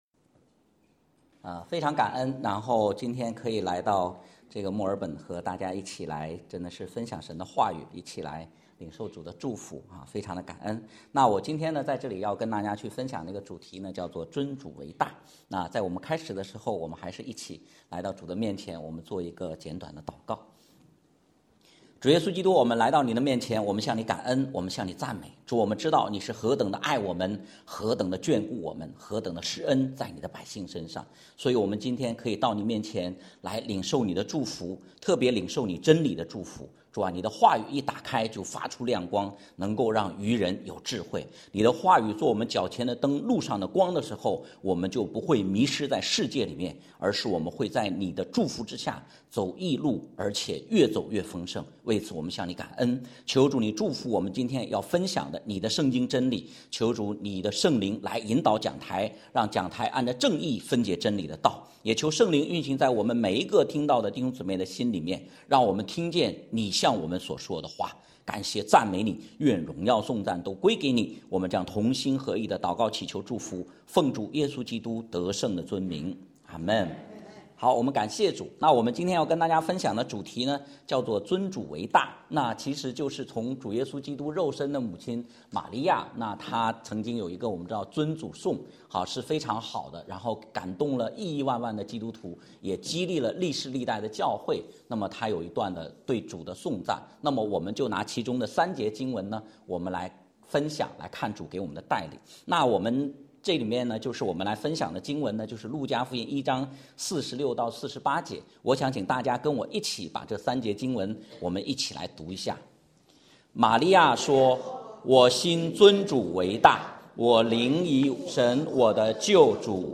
讲道录音 点击音频媒体前面的小三角“►”就可以播放： https